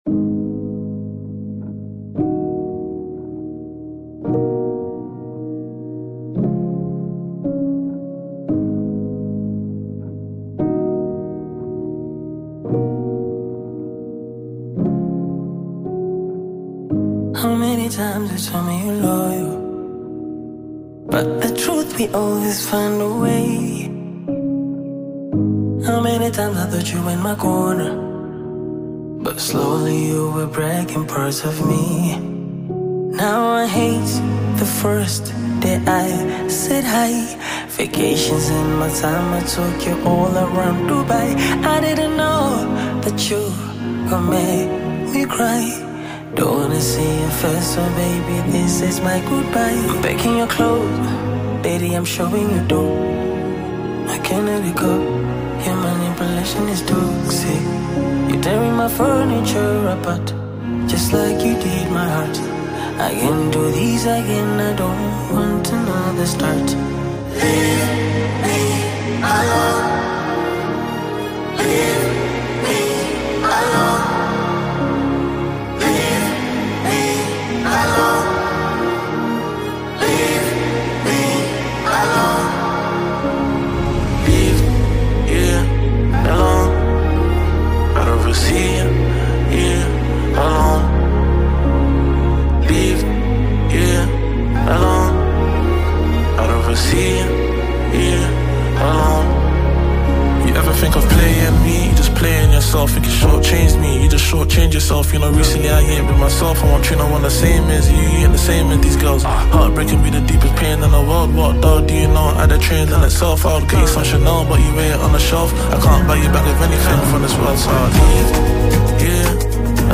AudioBongo flava
Genre: Bongo Flava